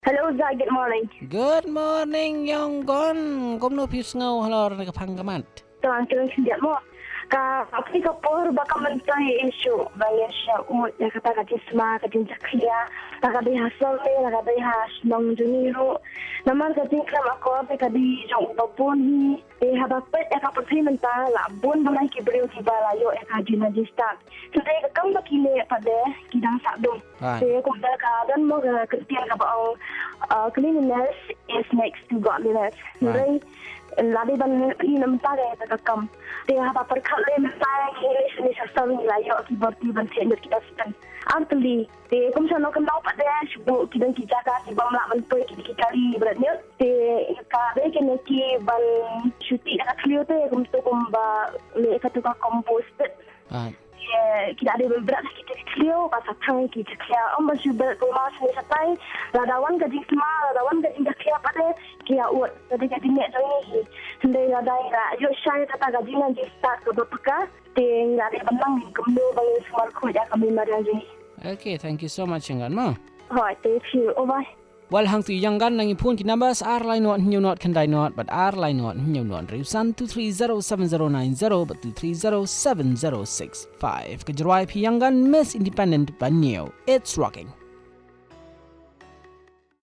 Caller 1 on reckless throwing of garbage in the city